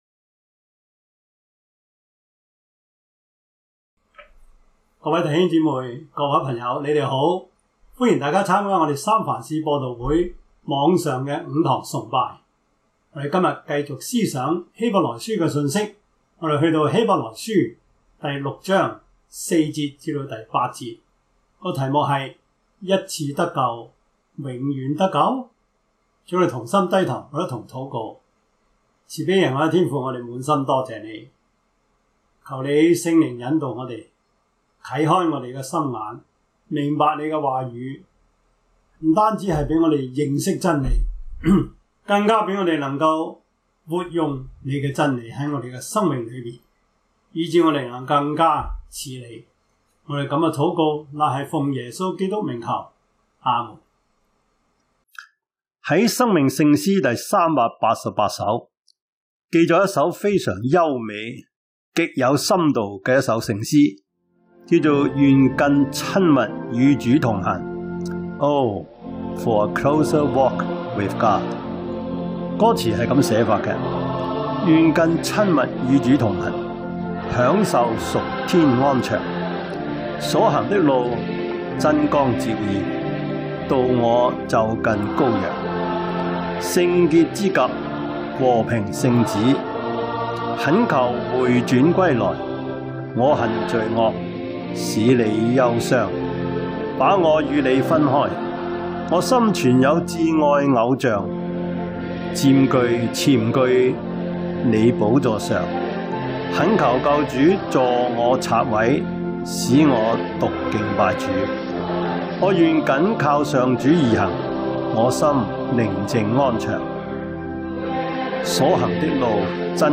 Service Type: 主日崇拜
Topics: 主日證道 « 如何認識神?